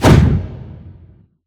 Punch Swing_HL_2.wav